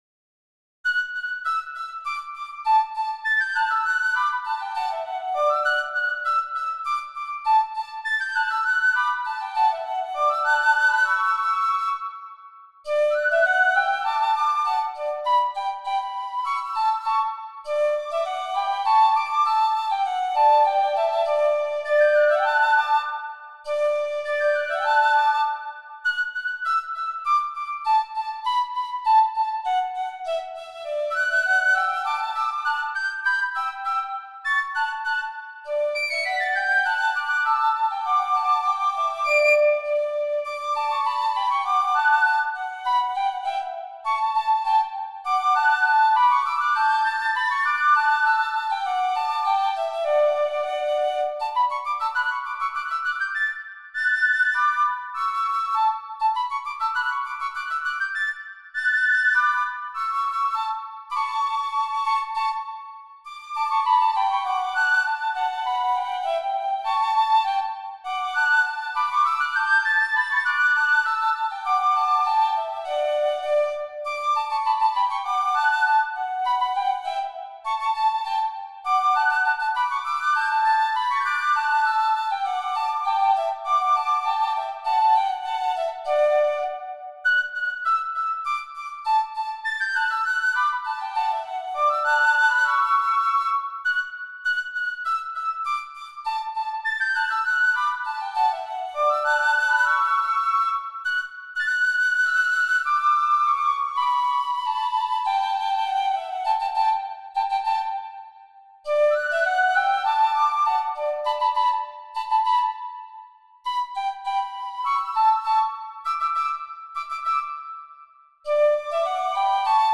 Piccolo Duos on Irish Themes